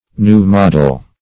New-model \New`-mod"el\